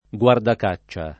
guardacaccia [ gU ardak #©© a ]